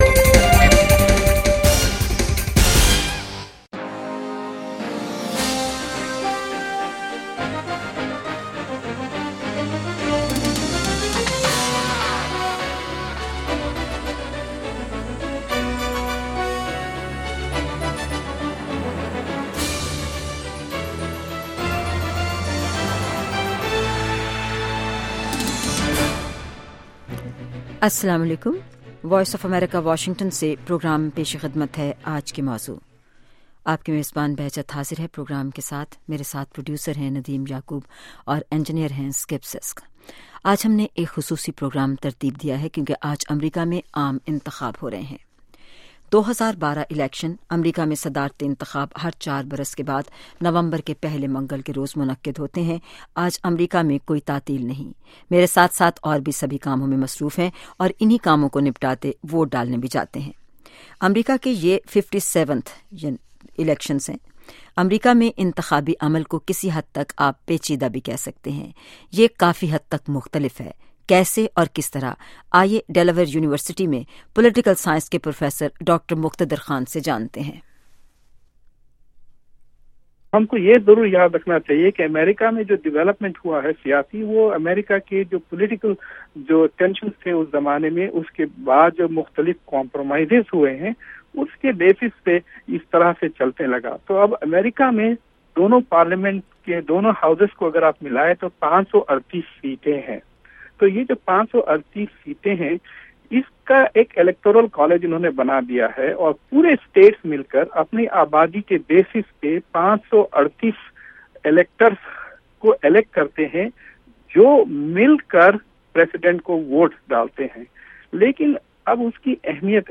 Today’s program was a smooth broadcast with 11 Participants & solid information about different aspects of Election 2012.
We then have one Democrat & one Republican who talked about their parties’ point of view. They explained the differences about pro-life & pro-choice, Gay rights & personal freedom. They talked about economic issues as well as foreign policy.